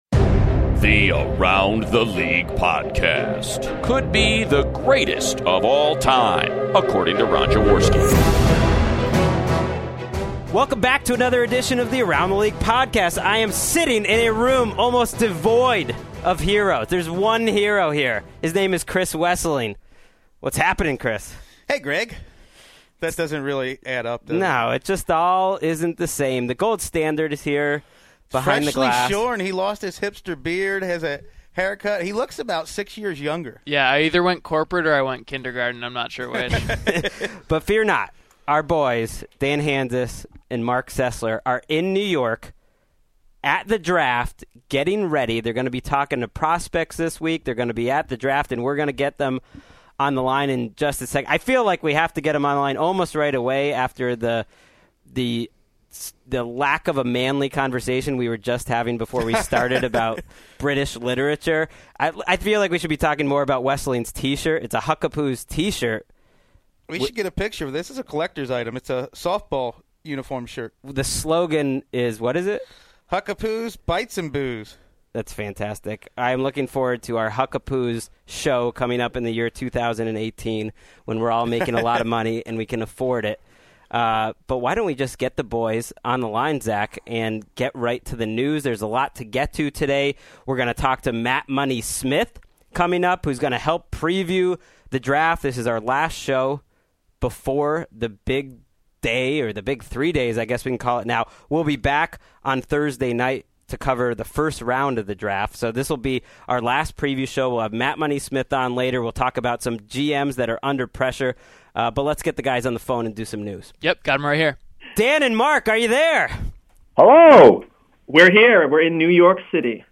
in studio